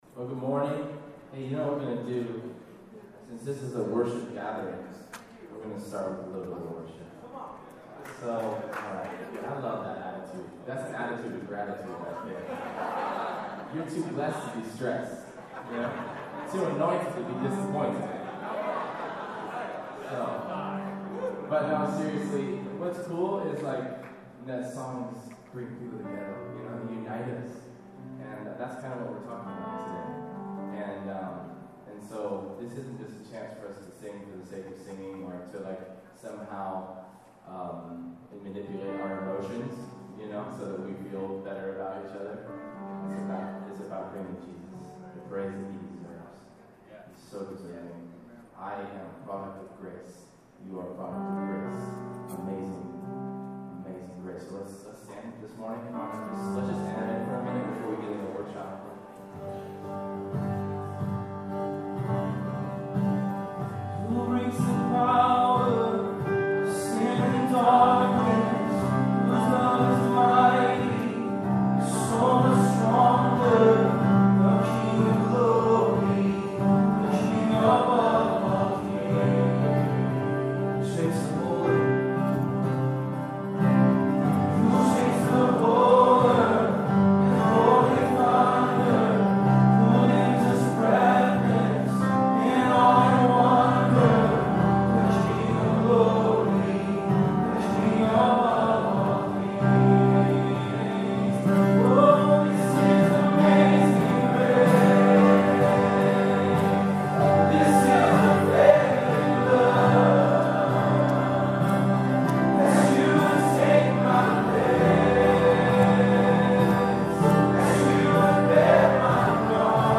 Workshop: Worshipers Leading - News + Resources
The live audio recording includes wonderful, practical ideas on fostering a culture of songwriting, contextualizing it to your city, as well as information about future networking opportunities.